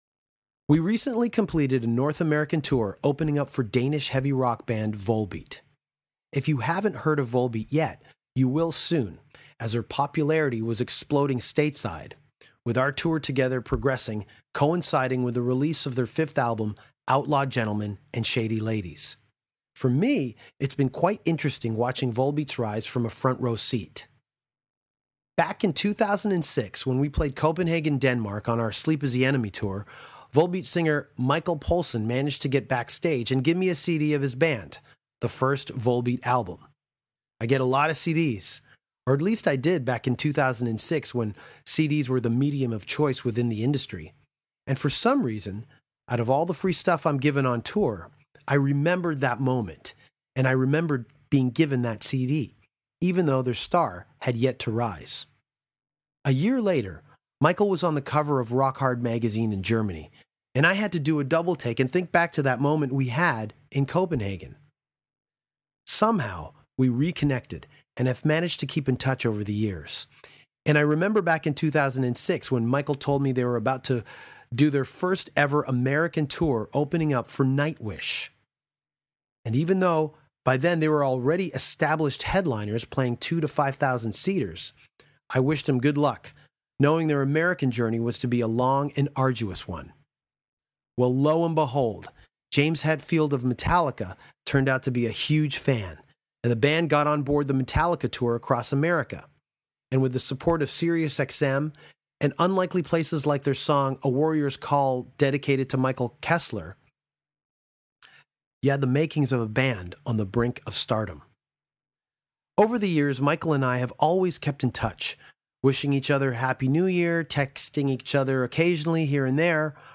Danko sits down with guitarist/producer Rob Caggiano to talk about The Damned Things, Anthrax, joining Volbeat, Temple Of The Black Moon and King Diamond.